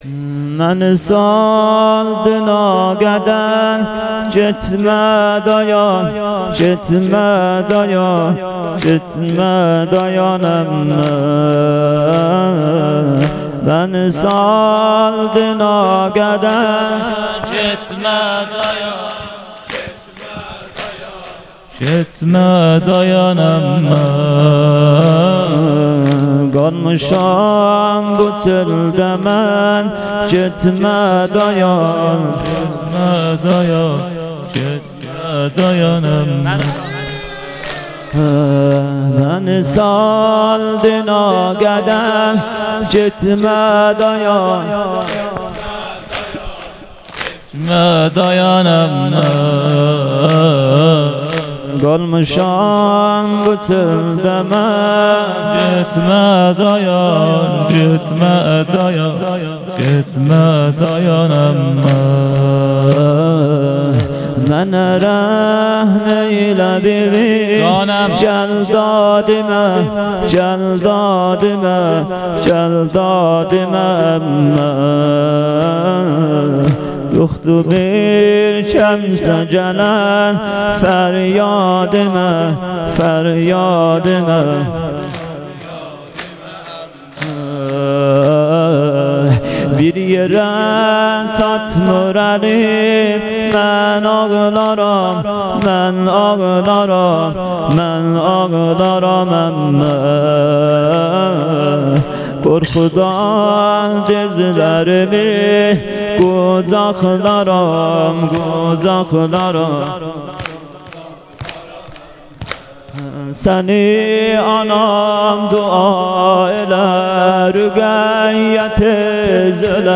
شب سوم محرم 98 - بخش چهارم سینه زنی(تک)